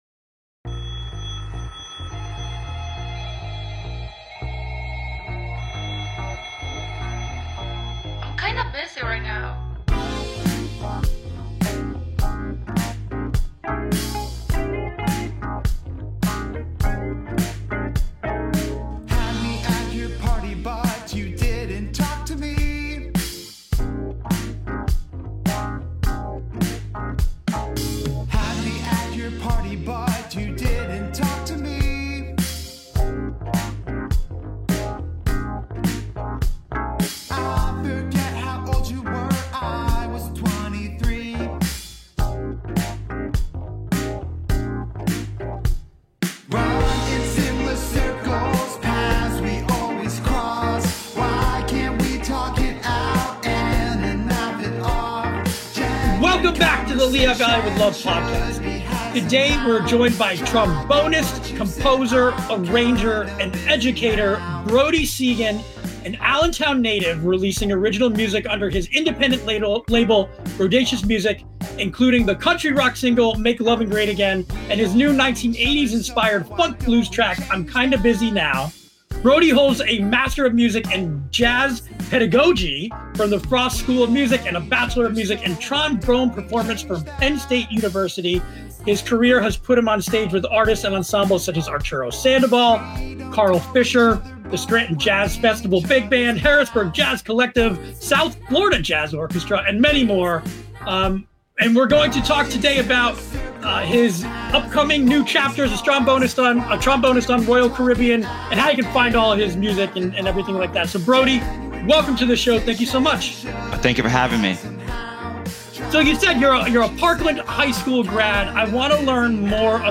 On this episode of the Lehigh Valley with Love Podcast, we sit down with Allentown born trombonist